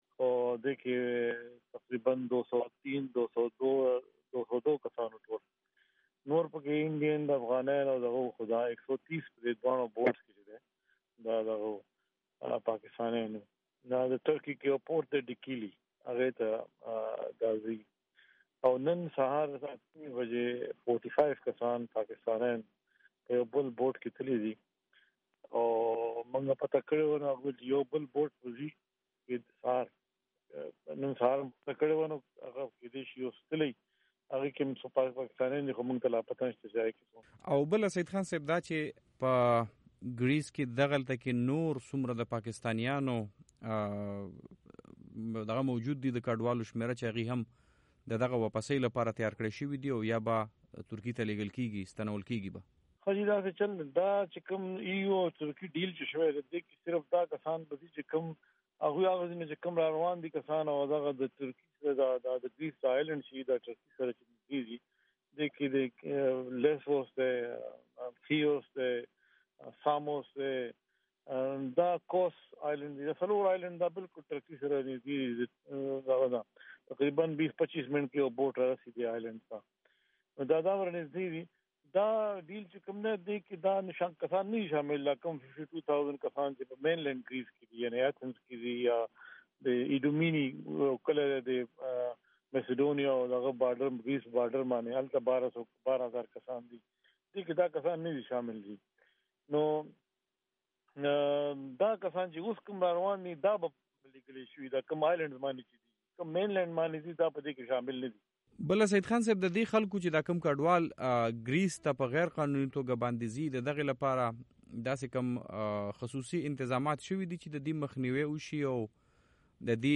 په یونان کې د پاکستان سفير سعيد خان مرکه